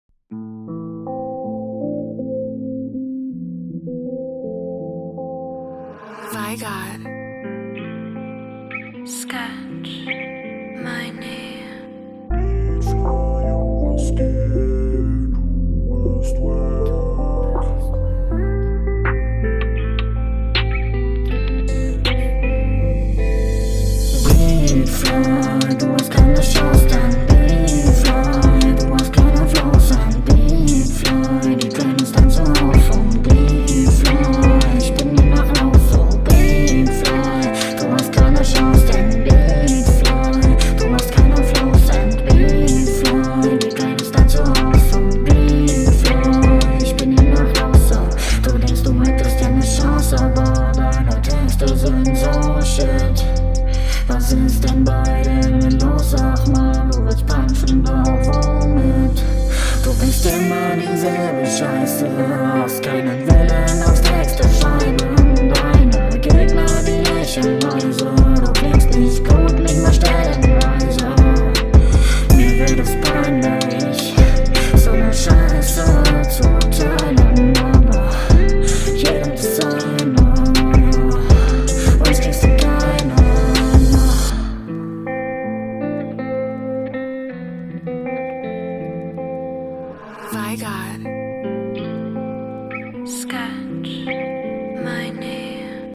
Man kann kaum etwas verstehen. Die Vocals beißen sich heftig mit dem Beat.